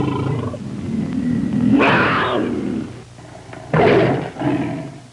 Angry Tiger Sound Effect
Download a high-quality angry tiger sound effect.
angry-tiger.mp3